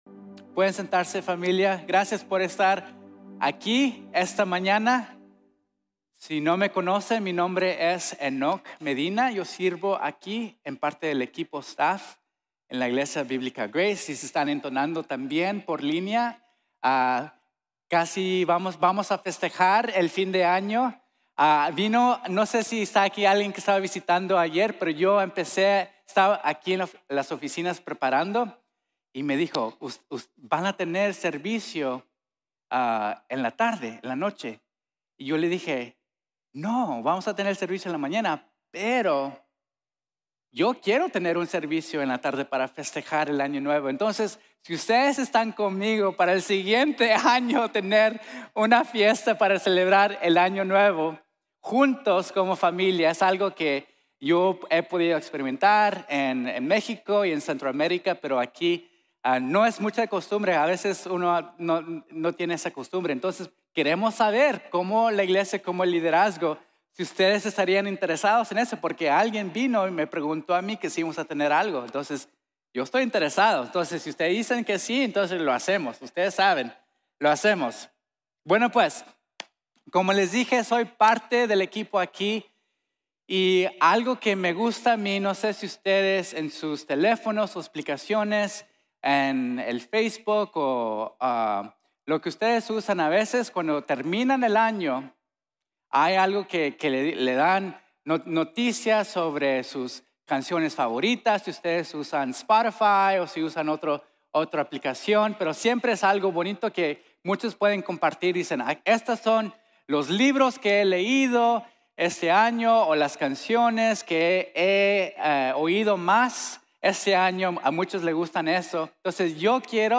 Ver realmente el favor de Dios | Sermon | Grace Bible Church